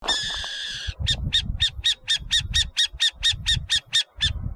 Lechucita Vizcachera (Athene cunicularia)
Nombre en inglés: Burrowing Owl
Fase de la vida: Adulto
Localidad o área protegida: Valle Inferior del Río Chubut (VIRCH)
Condición: Silvestre
Certeza: Observada, Vocalización Grabada
Lechucita-vizcachera1.mp3